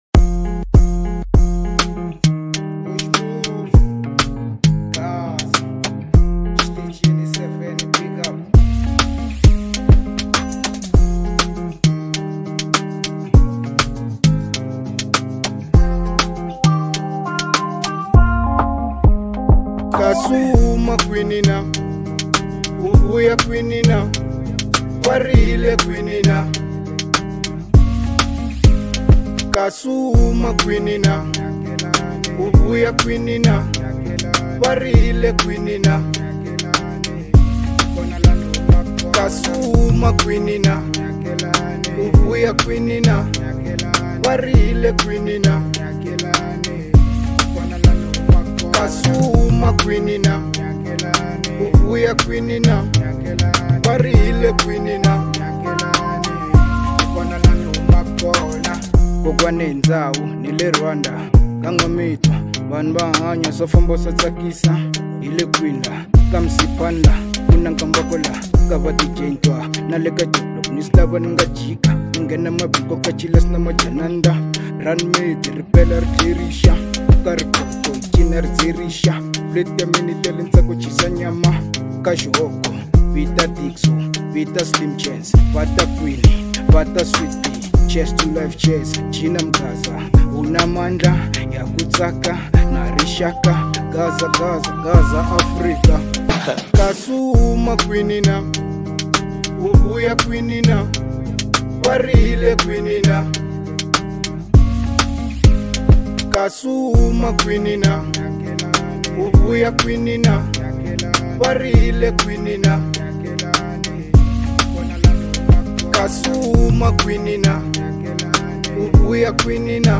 03:34 Genre : Hip Hop Size